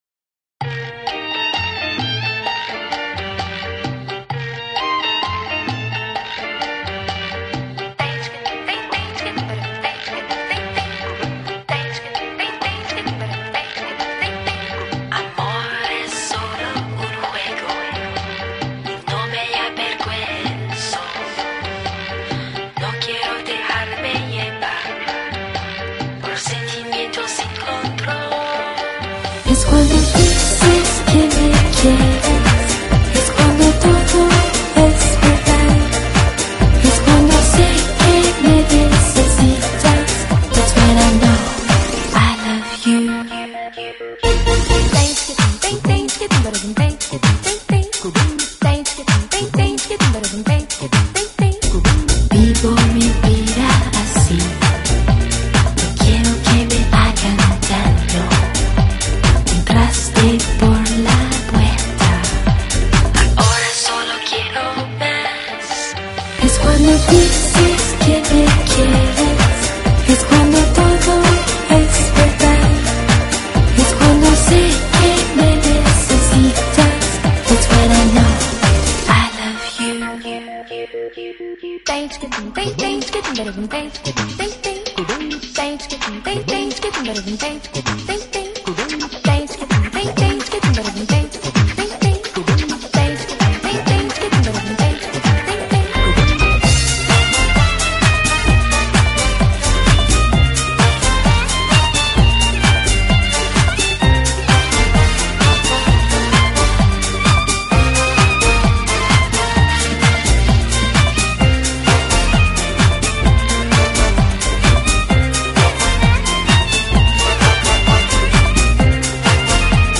音乐类型：New Age/Classical